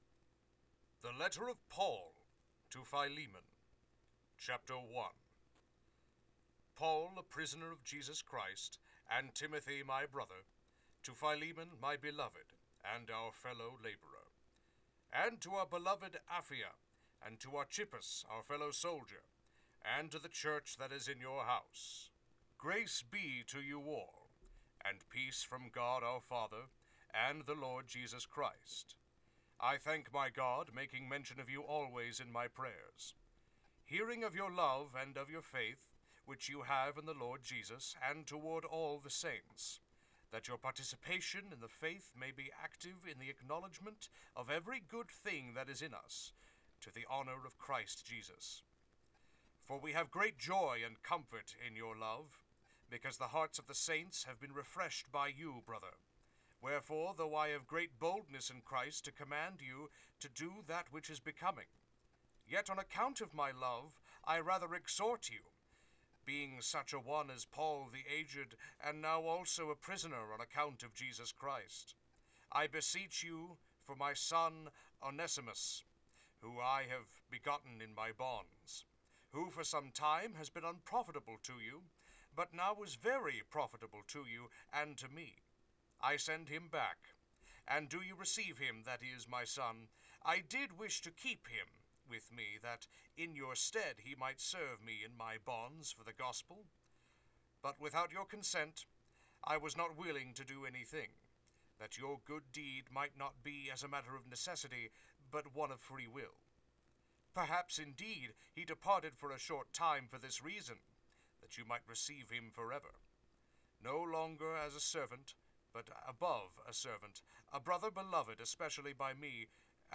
philemon-chapter-1-of-1.mp3